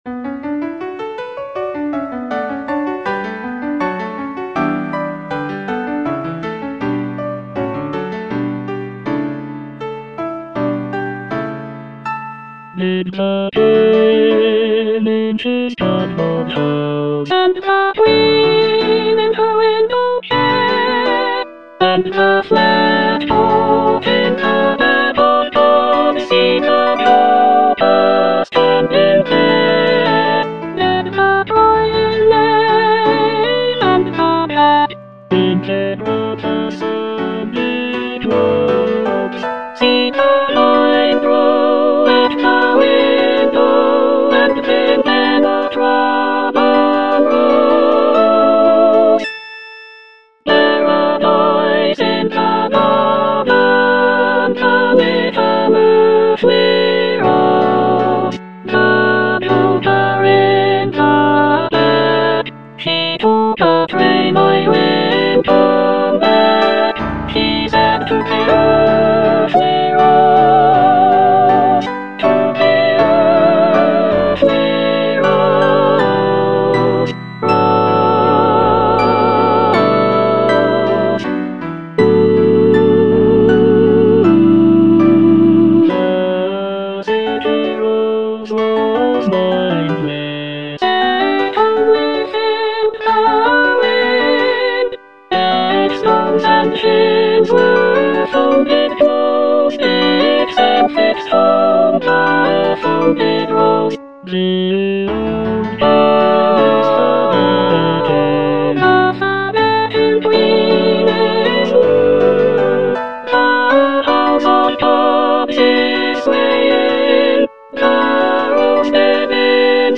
Soprano I (Emphasised voice and other voices)
choral work